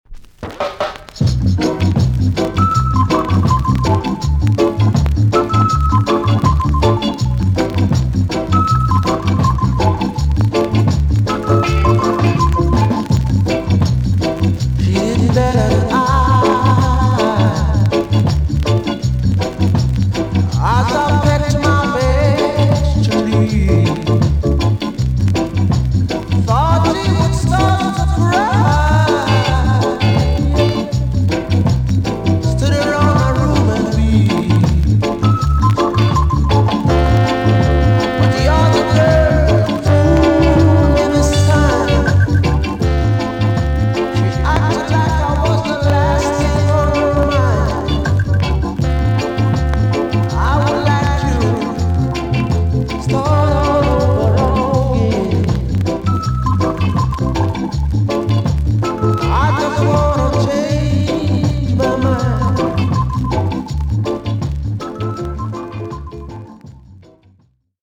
TOP >SKA & ROCKSTEADY
VG+ 少し軽いチリノイズがありますが良好です。